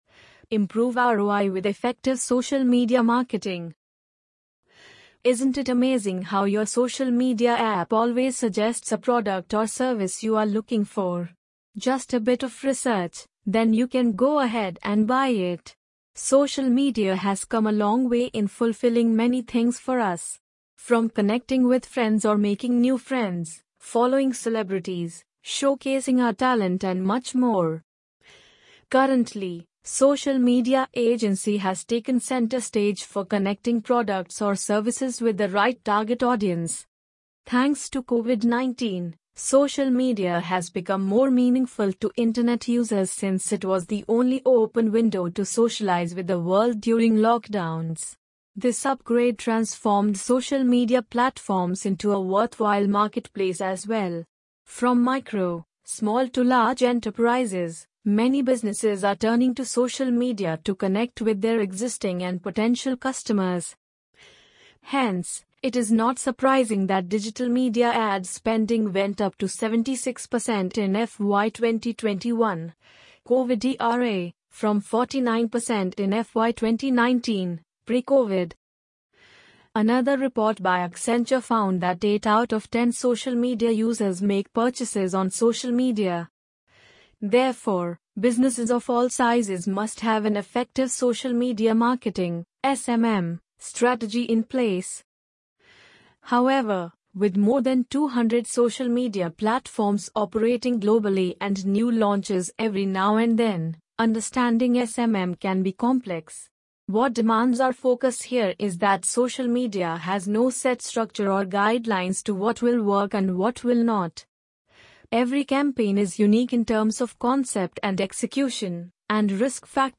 amazon_polly_7215.mp3